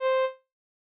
Violin.wav